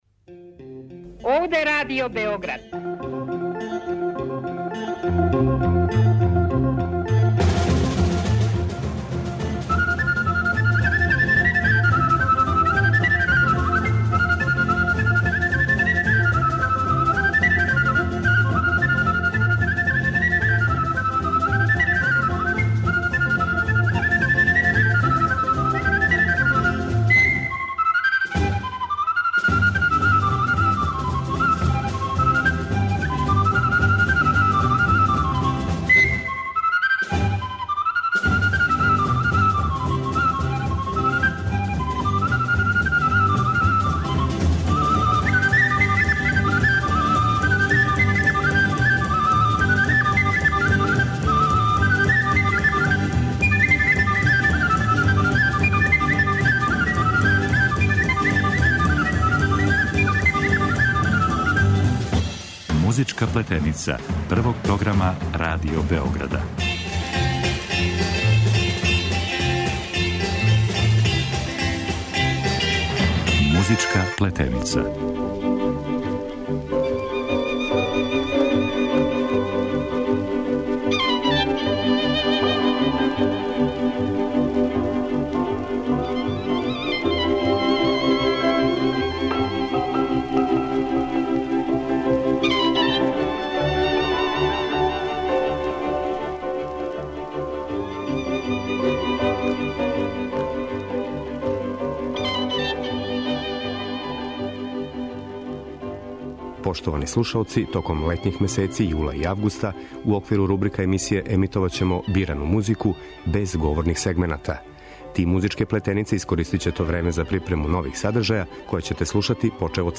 Током летњих месеци у оквиру рубрика емисије емитоваћемо бирану музику, без говорних сегмената.